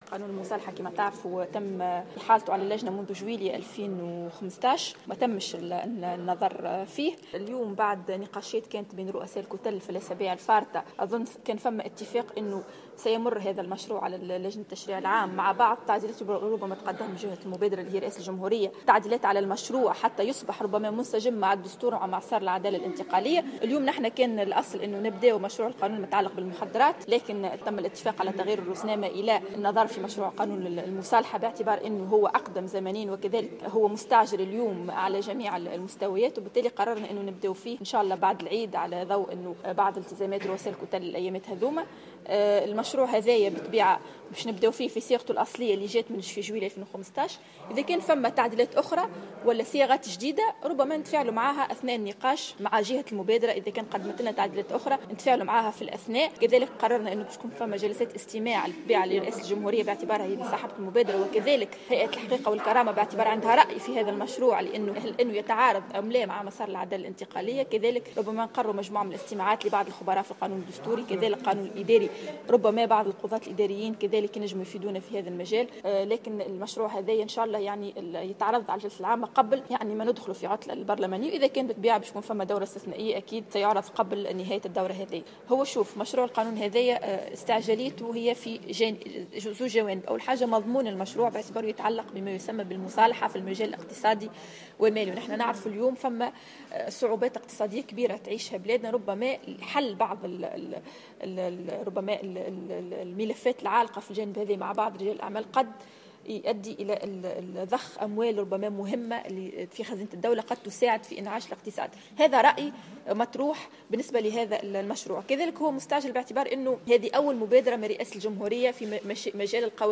وذكّرت في تصريحات صحفية أنه تم إحالة هذا المشروع على اللجنة منذ جويلية 2015 ولم يتم النظر فيه منذ ذلك التاريخ.